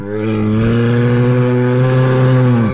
bear-groan-long.mp3